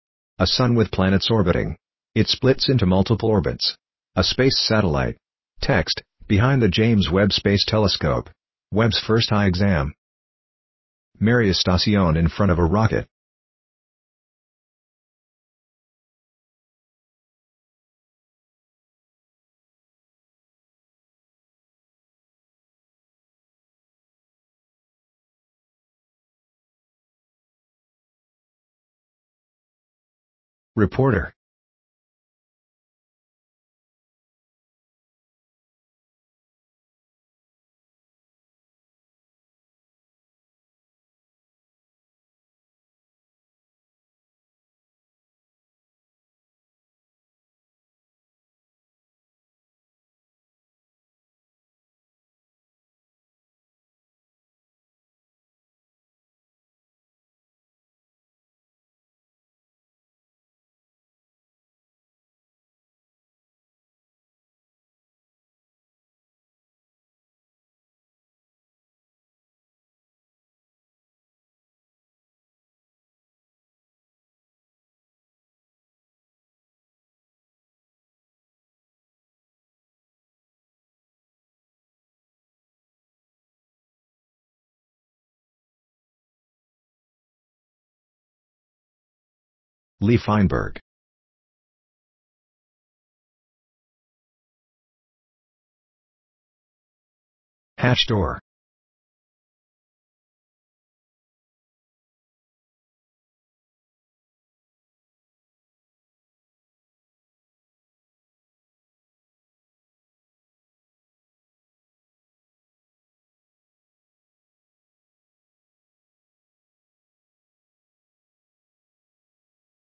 Audio Description.mp3